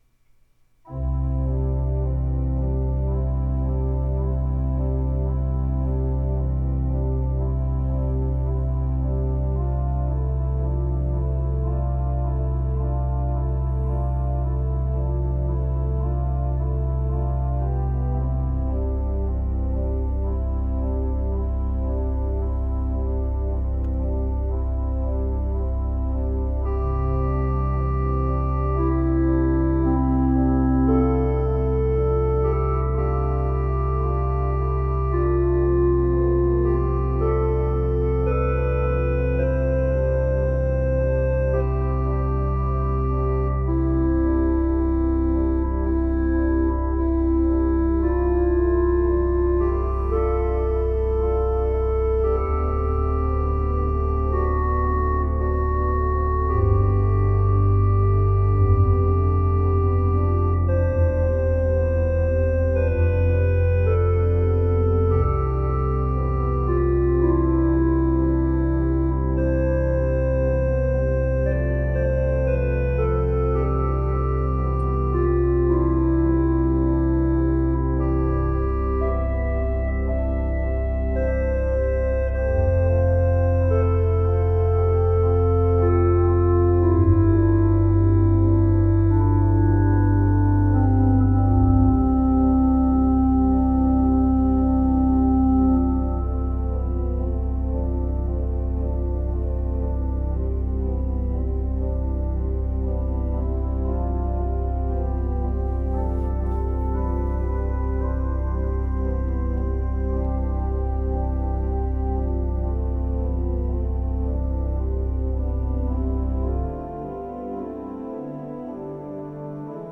Come, Ye Disconsolate is one of my favorite hymns.
This arrangement would be a great prelude.